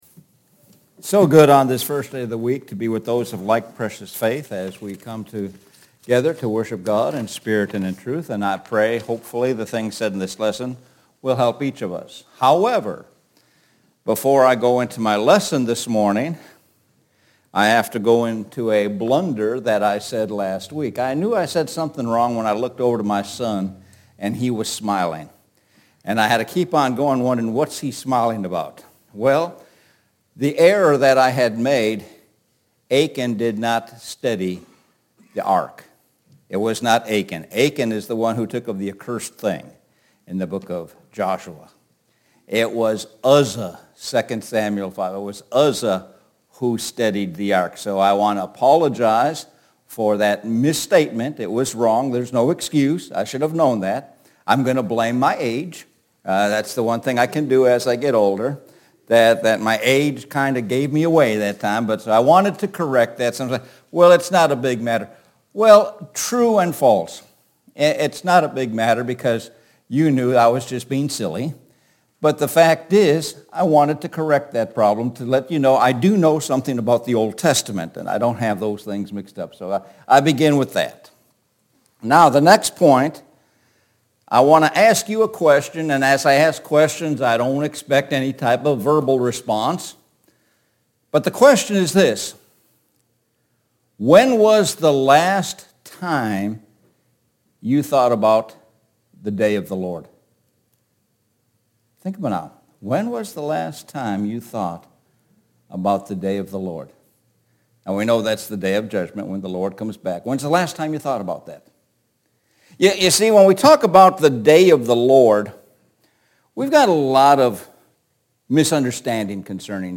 Sun AM Sermon